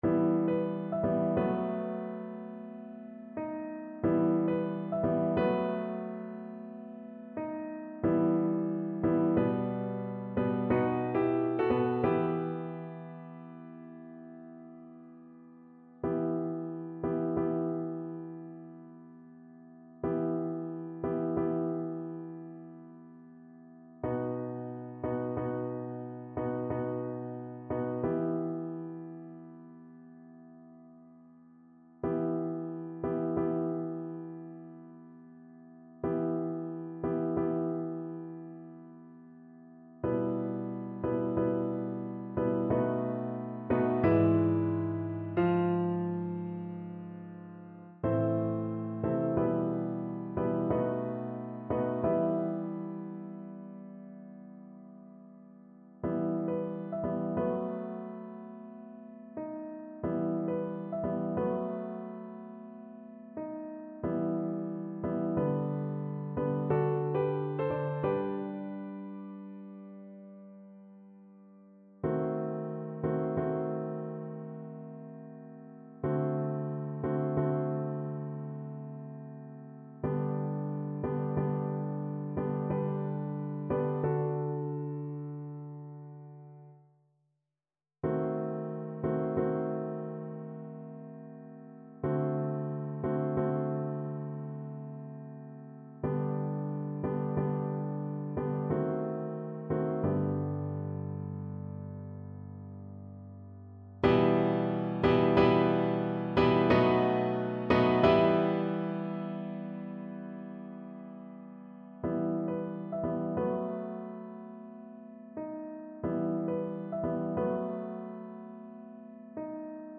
Play (or use space bar on your keyboard) Pause Music Playalong - Piano Accompaniment Playalong Band Accompaniment not yet available transpose reset tempo print settings full screen
Voice
3/4 (View more 3/4 Music)
B4-G6
E minor (Sounding Pitch) (View more E minor Music for Voice )
Langsam =c.45